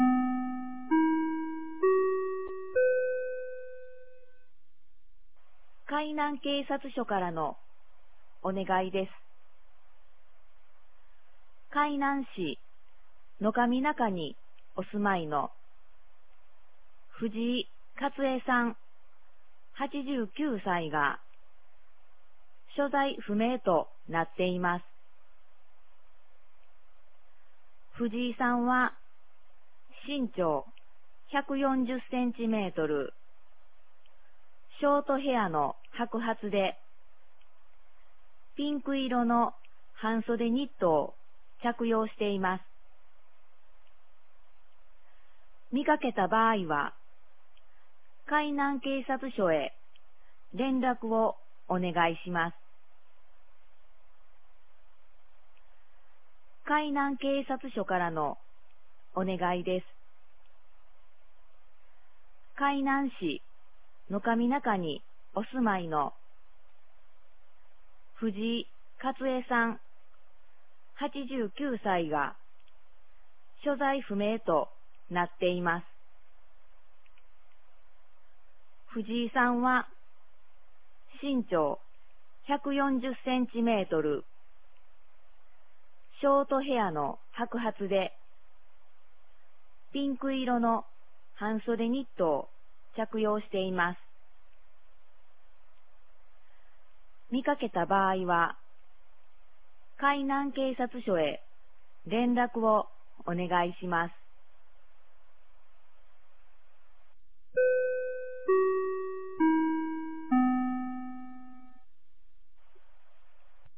2023年09月16日 10時31分に、紀美野町より全地区へ放送がありました。